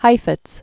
(hīfĭts), Jascha 1901-1987.